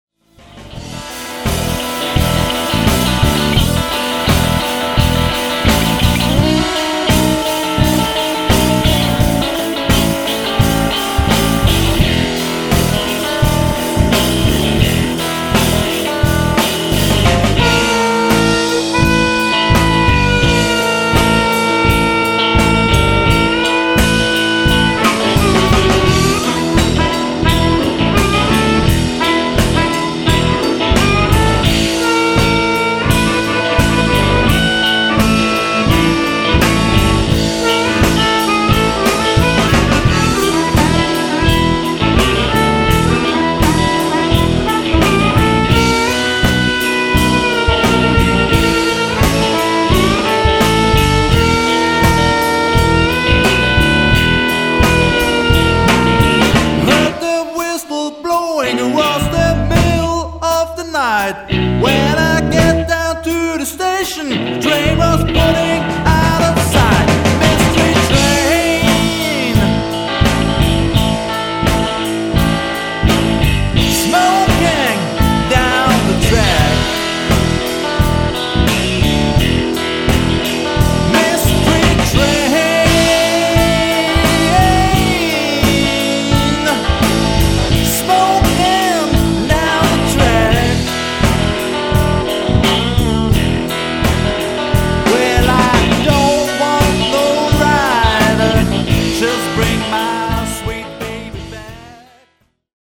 Blues&Rock´n´Roll-Band aus OÖ